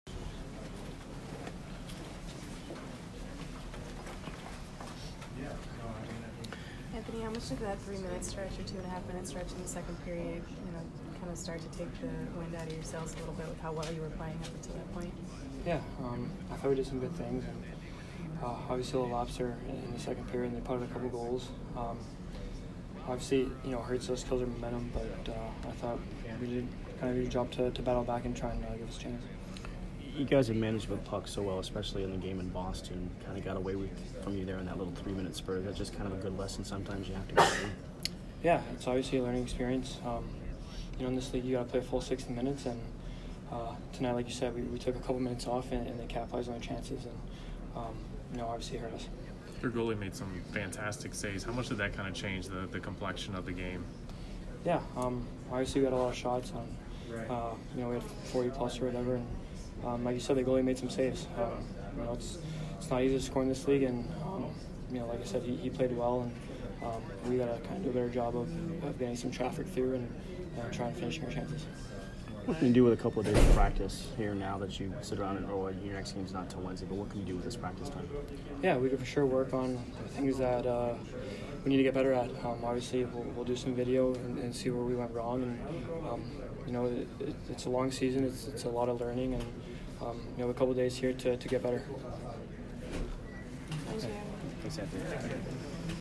Anthony Cirelli post-game 10/19